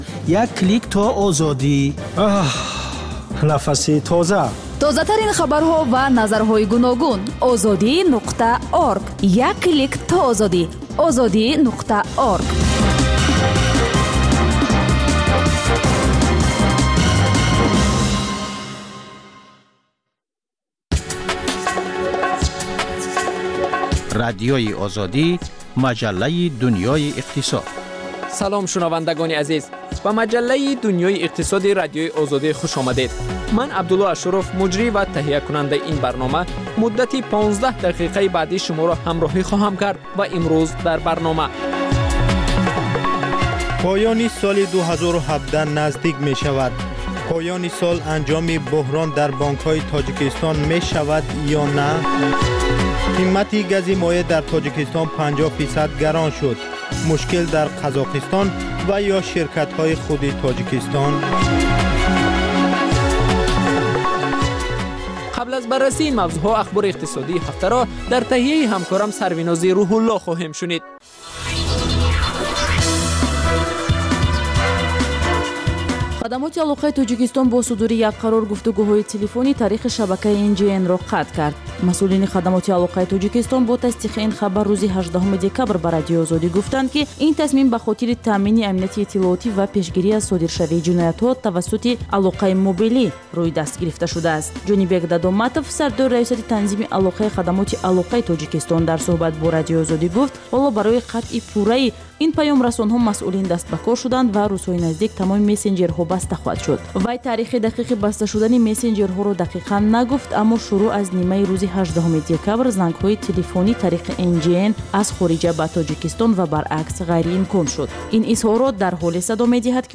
Гуфтори вижаи Радиои Озодӣ аз ҳаёти ҷавонони Тоҷикистон ва хориҷ аз он. Дар ин барнома таҳаввулоти ҷавонон аз дидгоҳҳои сиёсиву иҷтимоӣ, фарҳанги маданӣ тақдим мешавад